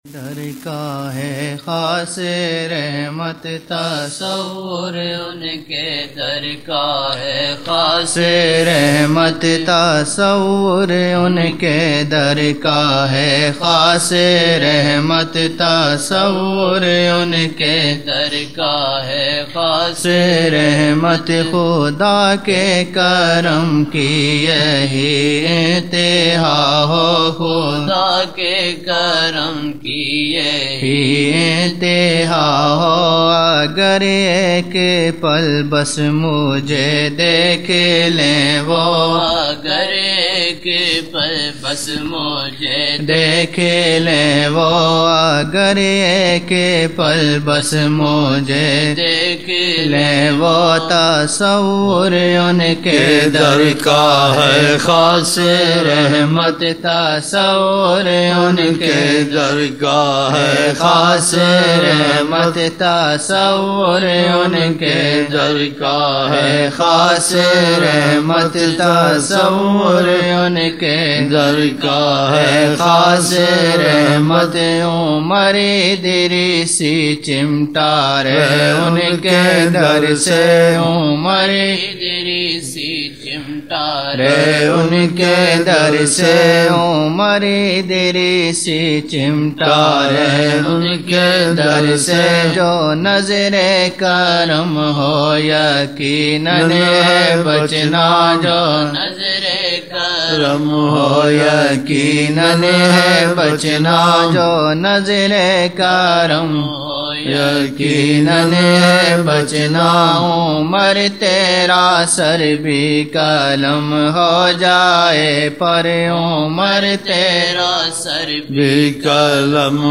17 November 1999 - Zohar mehfil (9 Shaban 1420)
Naat shareef